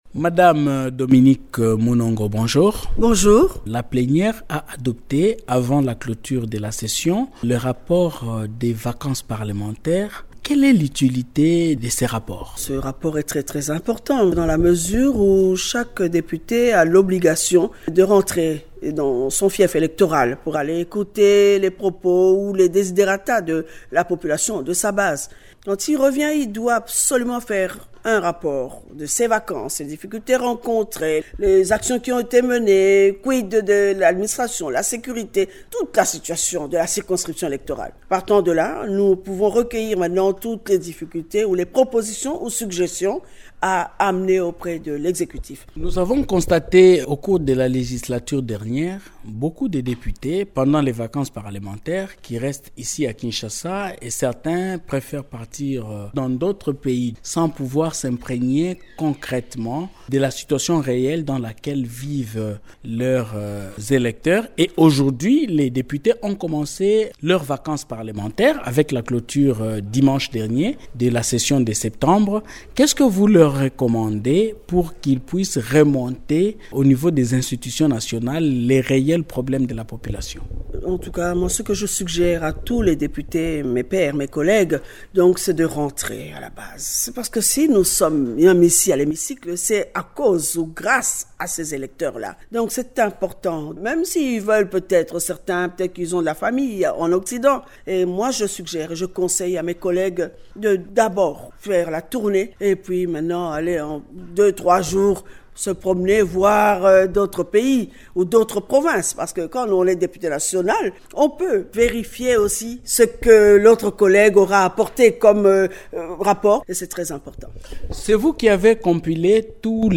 Dans une interview accordée mercredi 18 décembre à Radio Okapi, Dominique Munongo s’est félicitée de l’adoption par la plénière du rapport des vacances parlementaires de juin à septembre 2024 dernier.